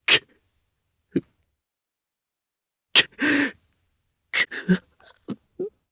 Kain-Vox_Sad_jp.wav